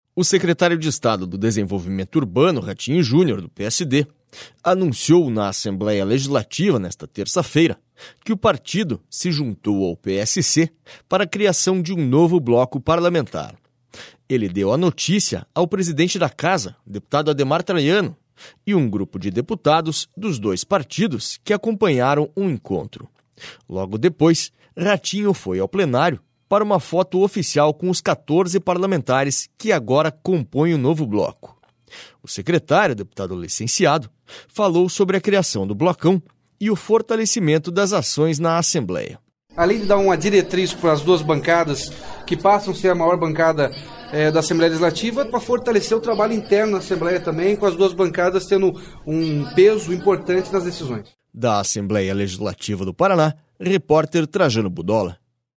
Ratinho Junior fala na Assembleia sobre a criação do bloco PSD/PSC